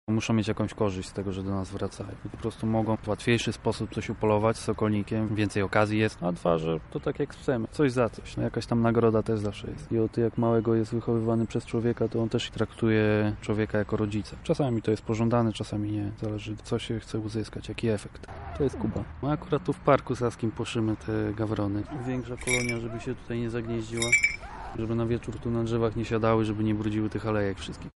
sokolnik